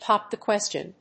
póp the quéstion
発音